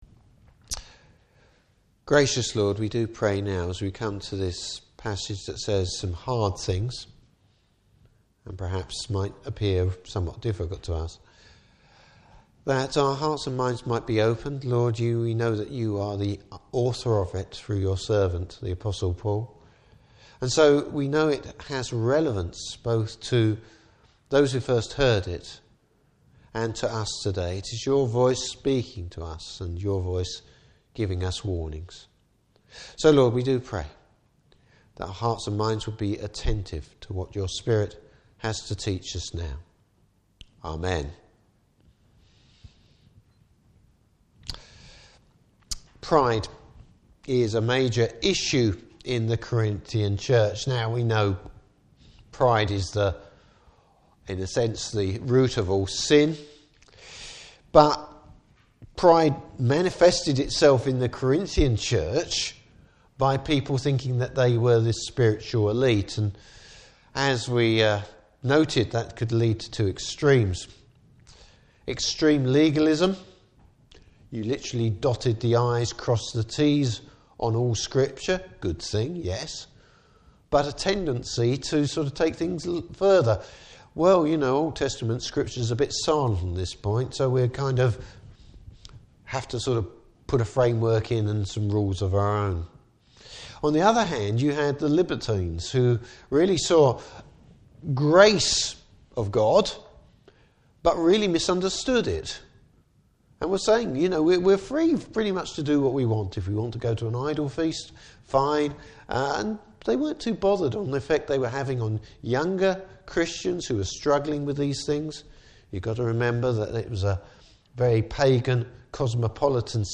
Service Type: Morning Service Why the sin of pride is so dangerous?